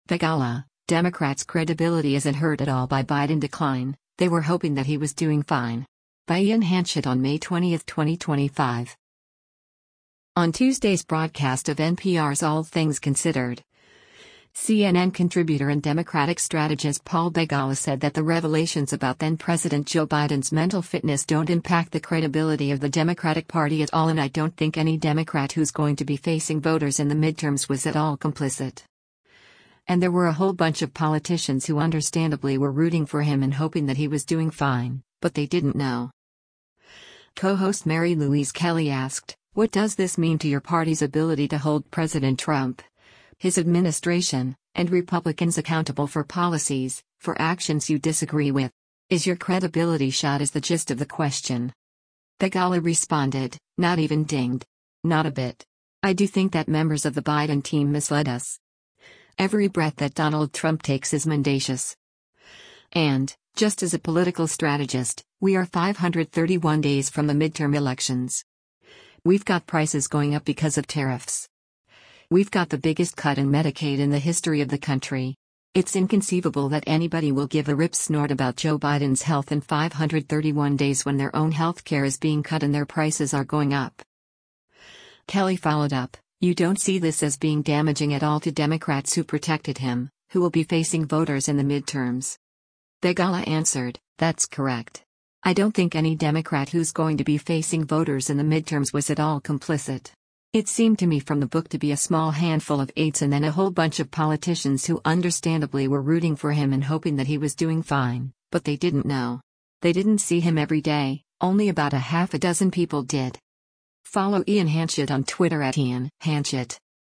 On Tuesday’s broadcast of NPR’s “All Things Considered,” CNN Contributor and Democratic strategist Paul Begala said that the revelations about then-President Joe Biden’s mental fitness don’t impact the credibility of the Democratic Party at all and “I don’t think any Democrat who’s going to be facing voters in the midterms was at all complicit.”